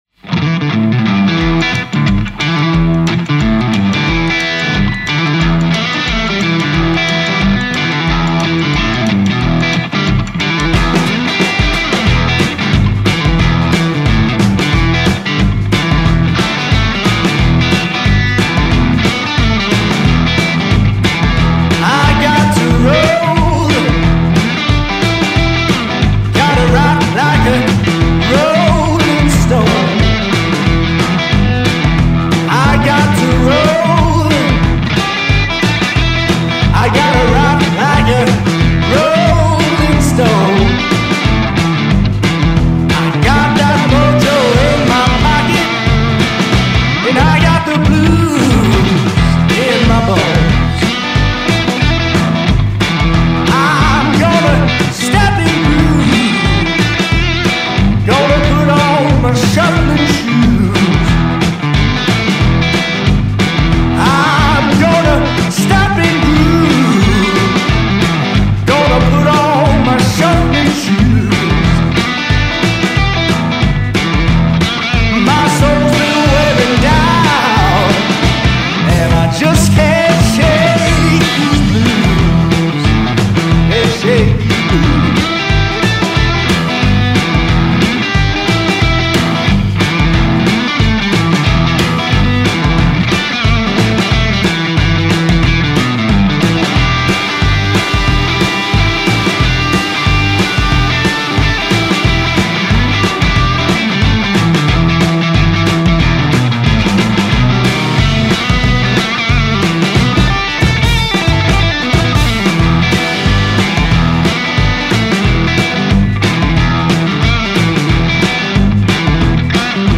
blues roots rock and soul band
Guitar